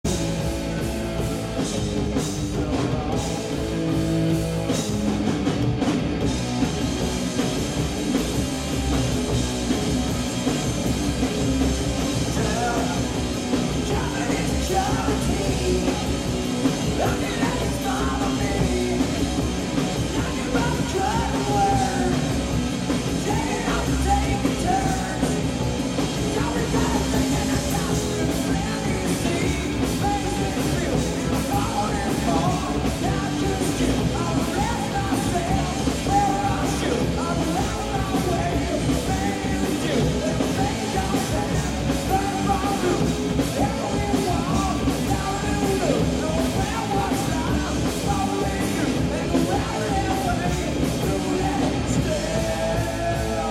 Sir Henry's Pub, Cork, IE
Audio: 9/10 Une audience de très bonne qualité.